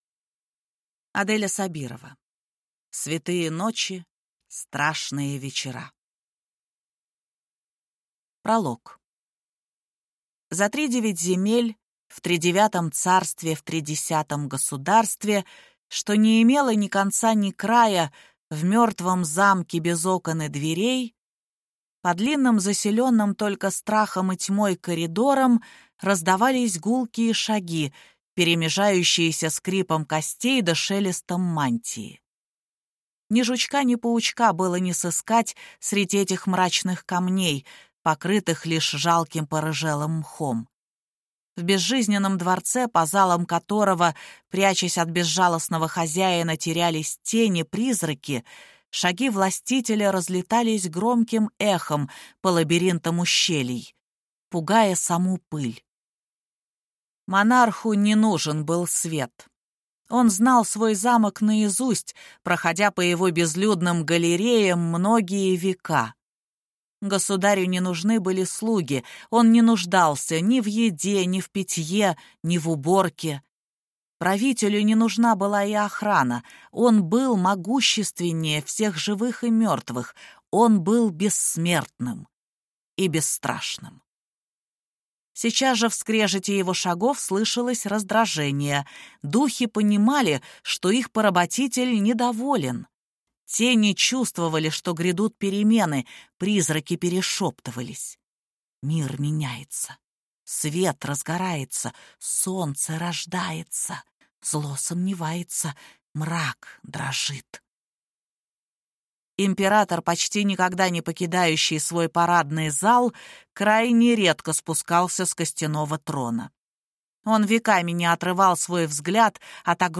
Аудиокнига Святые ночи. Страшные вечера | Библиотека аудиокниг
Прослушать и бесплатно скачать фрагмент аудиокниги